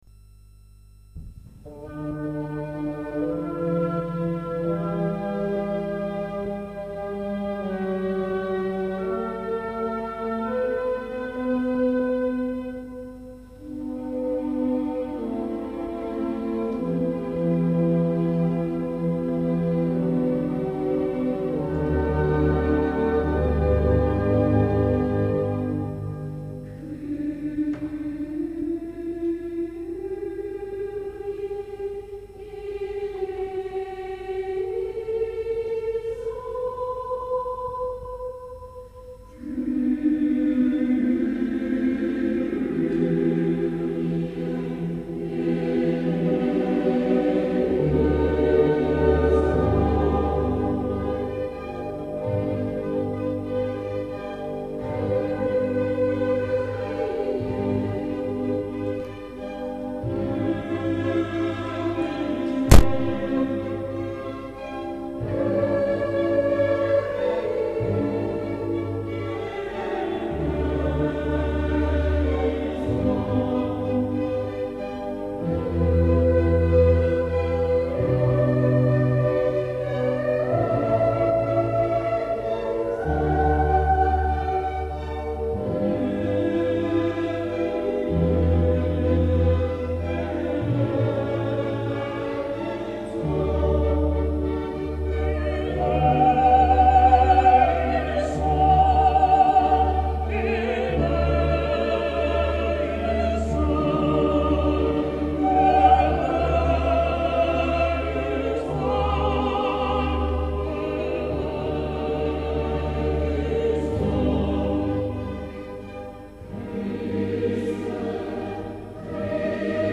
A l'invitation de ACJ Macon, concert  "Stiftskantorei & Kammerorchester Neustadt" St-Pierre MACON
Extraits du concert